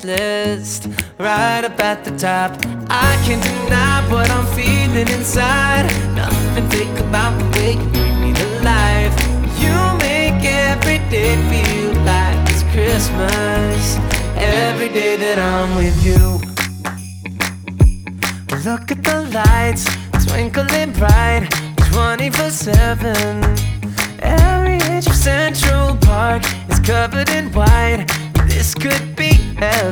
Genre: Holiday